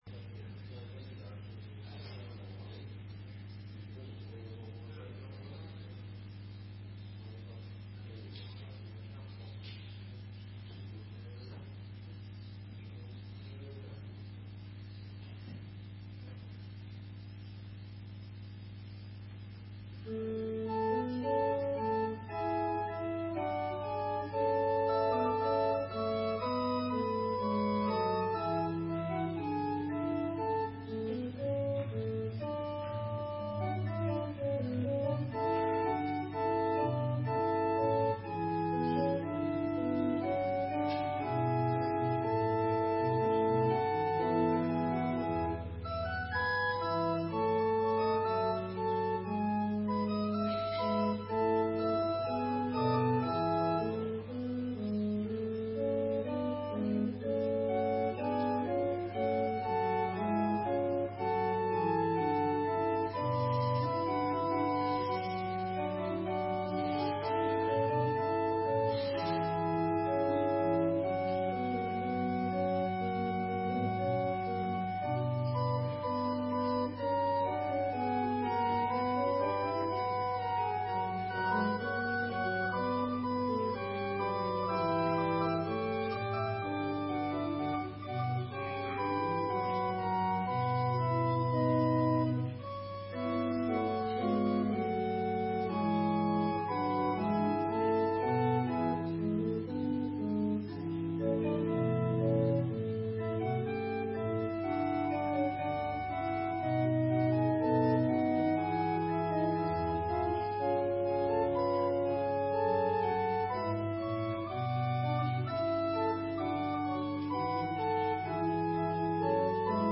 Opgenomen kerkdiensten